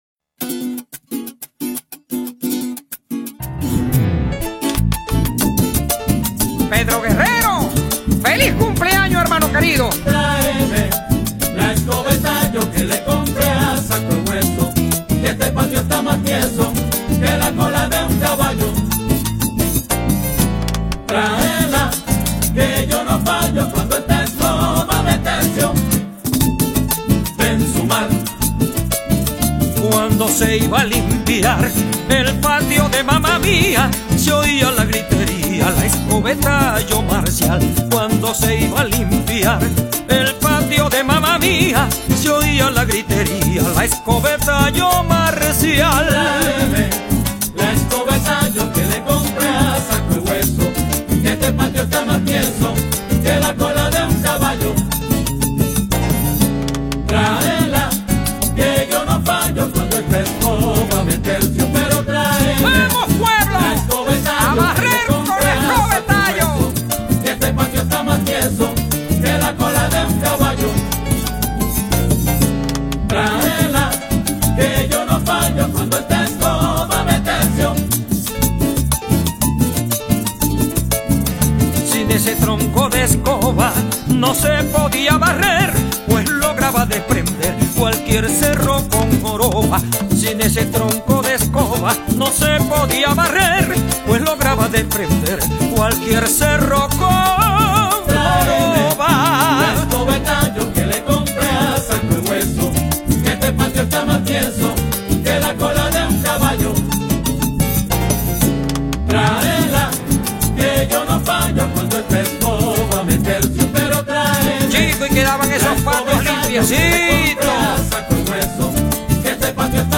Gaitas 2011 en aacPlus